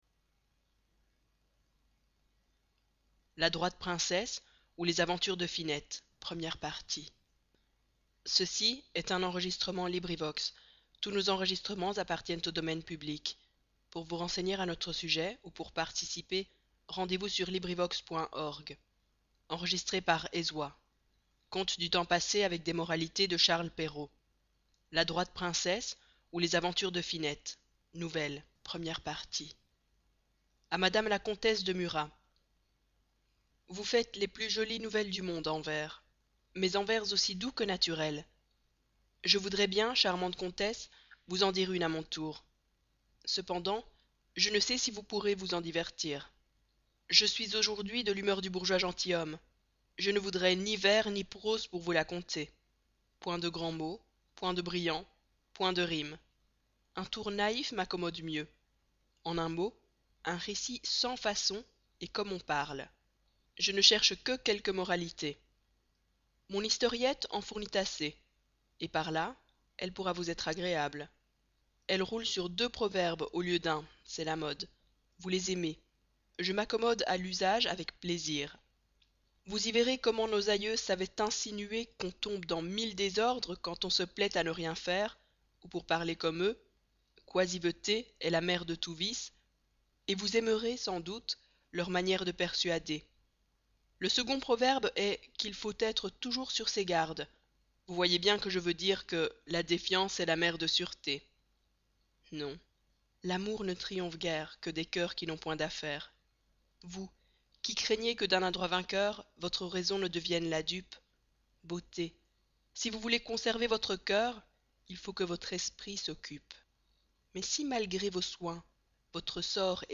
LibriVox recordings